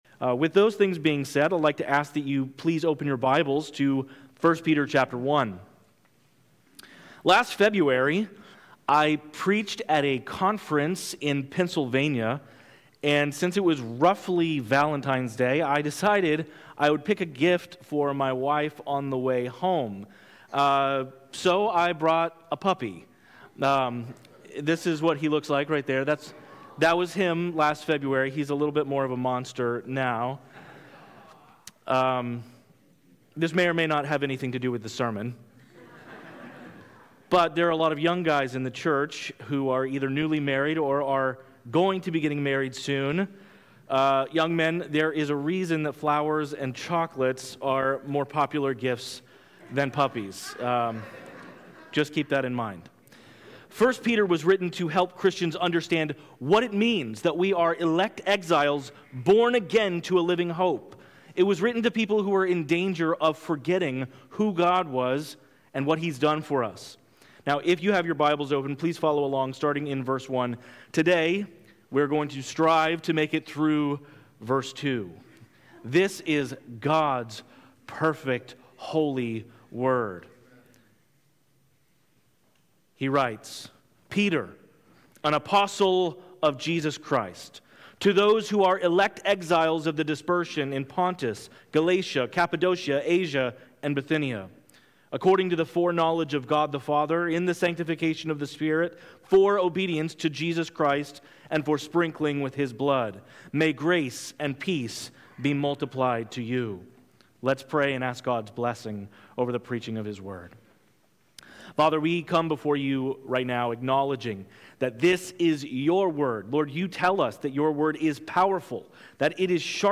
This podcast contains the sermons preached at Levittown Baptist Church in Levittown, New York.